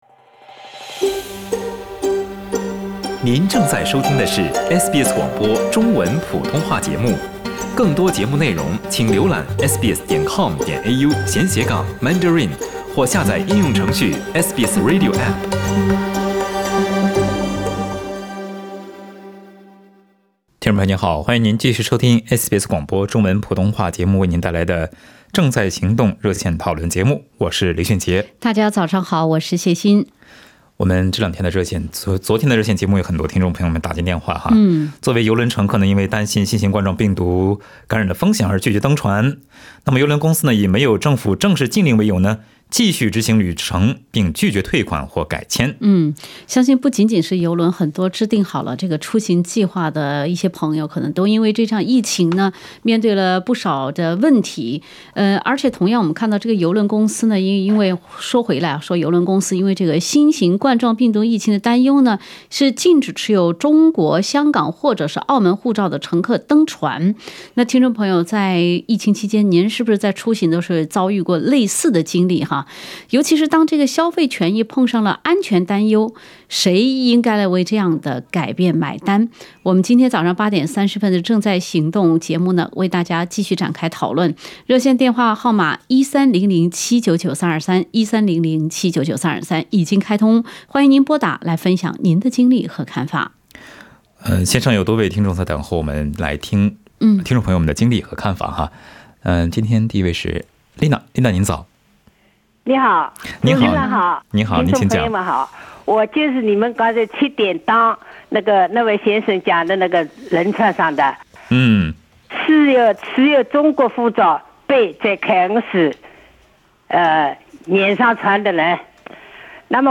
同样，有邮轮公司出于新冠病毒疫情的担忧，禁止持有中国、香港或澳门护照的乘客登船。 在本期的《正在行动》热线节目中，听众朋友分享了自己旅程因担忧新冠疫情而改变的经历。